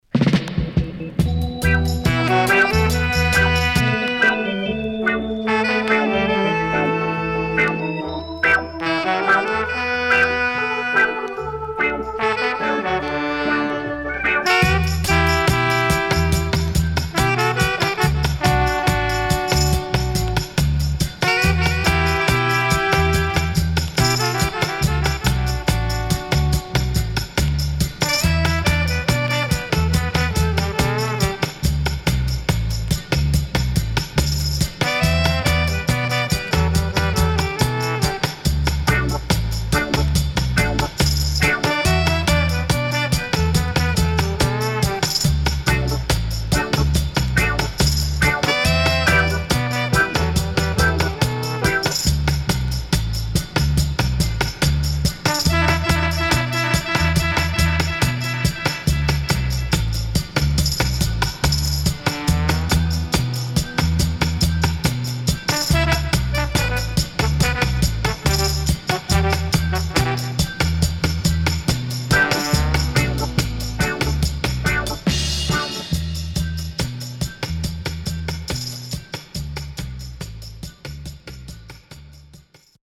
SIDE A:少しチリノイズ、プチノイズ入りますが良好です。